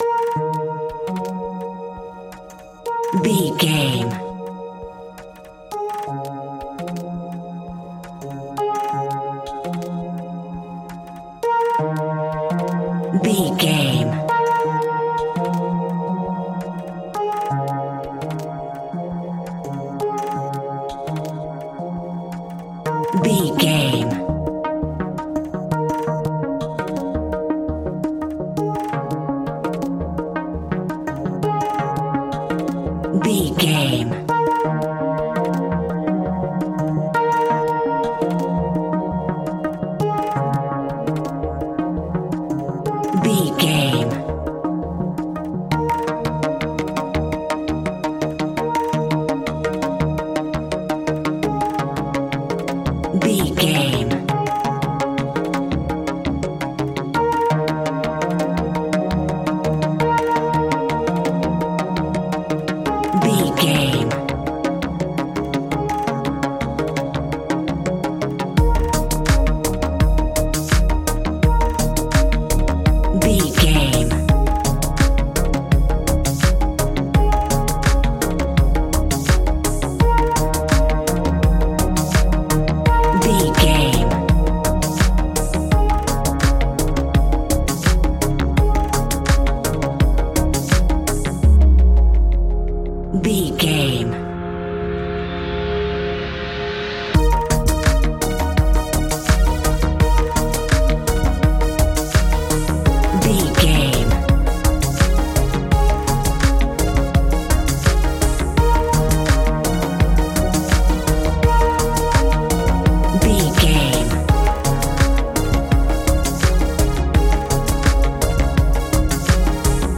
Ionian/Major
D♯
electronic
techno
trance
synthesizer
synthwave
instrumentals